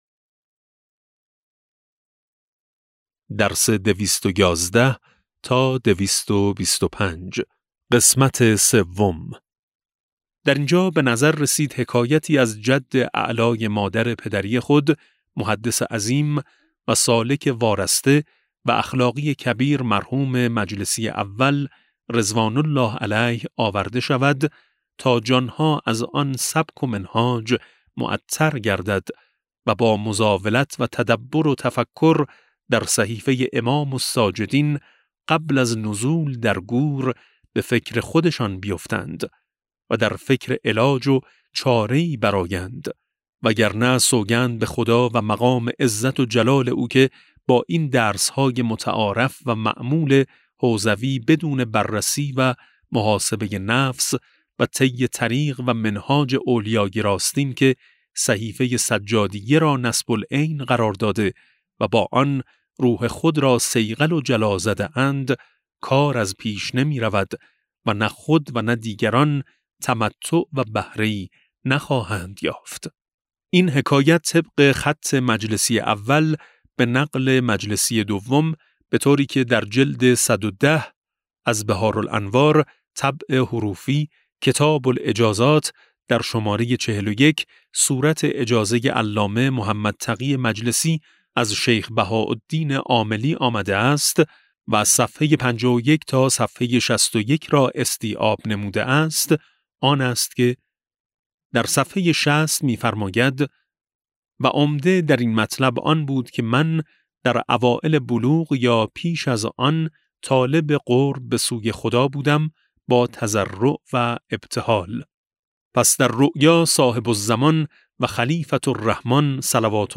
کتاب صوتی امام شناسی ج15 - جلسه3